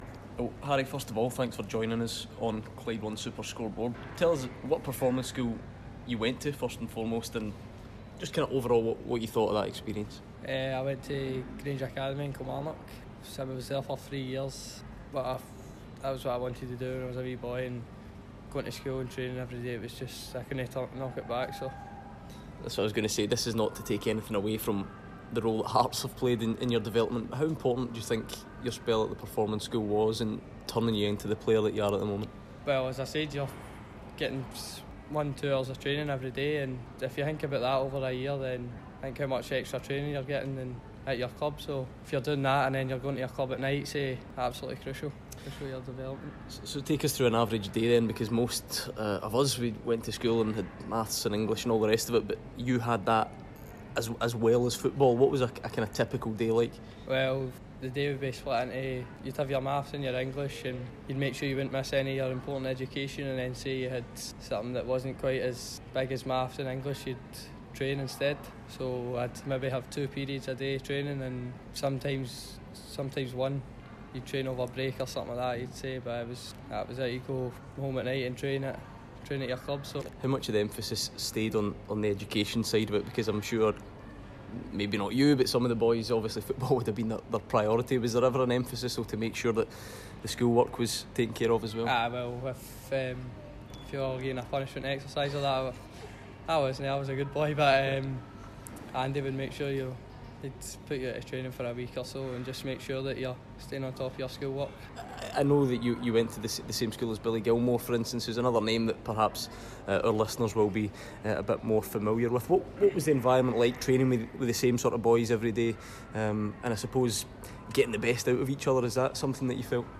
After exploding onto the scene at the age of 16, Hearts midfielder Harry Cochrane tells Clyde 1 Superscoreboard about his journey through the SFA's Performance schools as part of our week long look into grassroots football.